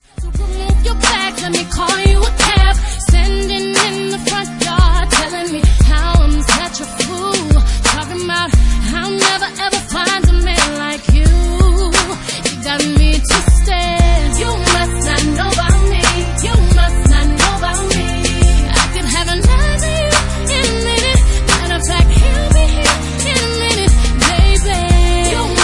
• Pop Ringtones